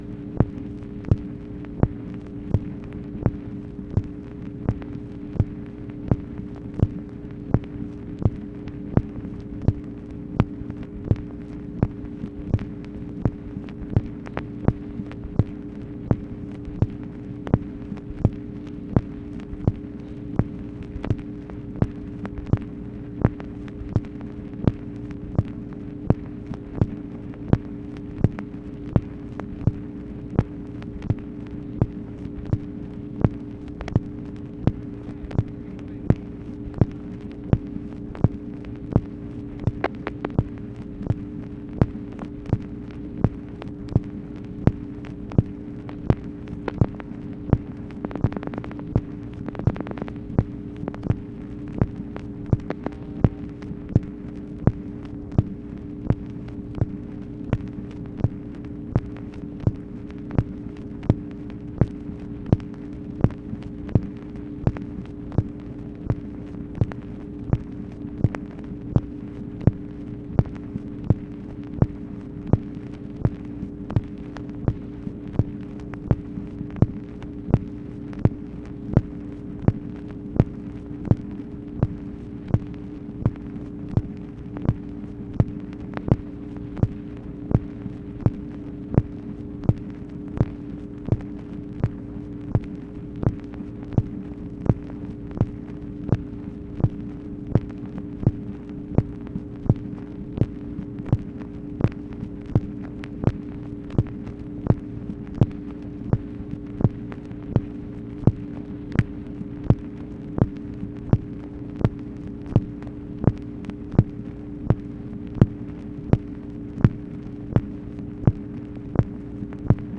MACHINE NOISE, April 1, 1965
Secret White House Tapes | Lyndon B. Johnson Presidency